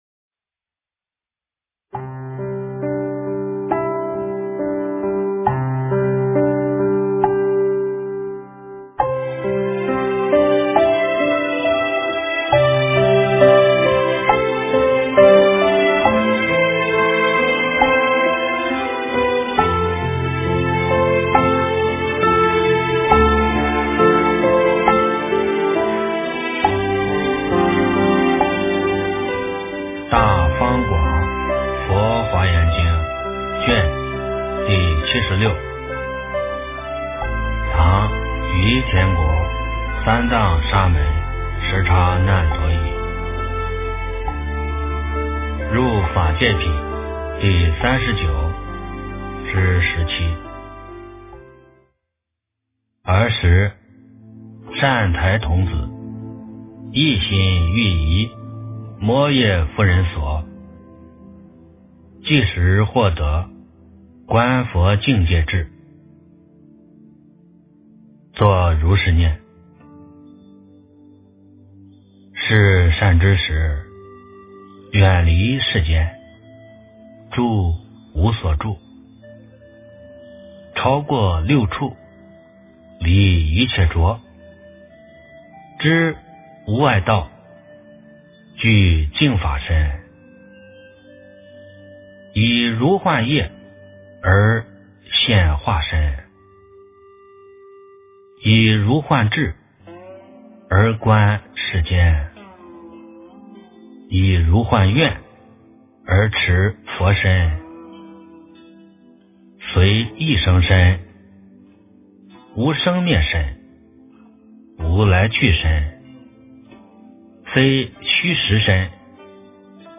《华严经》76卷 - 诵经 - 云佛论坛